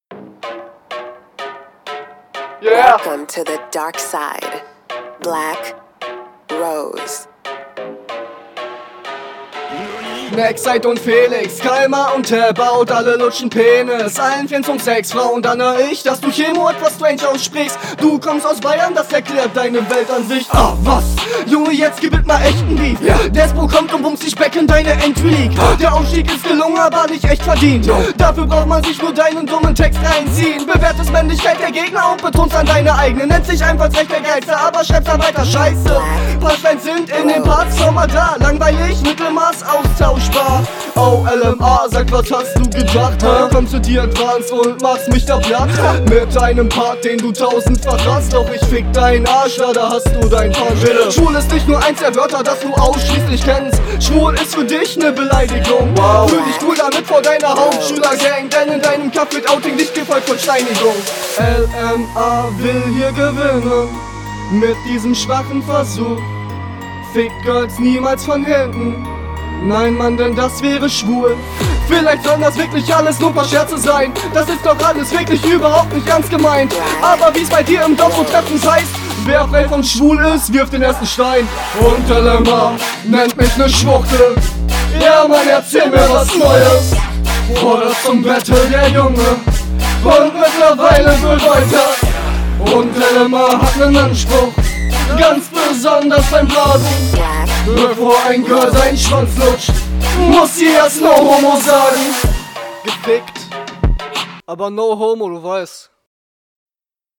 find dich nicht soo geil auf dem beat. stimme einfach etwas dünn und könntest auch …
Direkt mehr Power in der Stimme.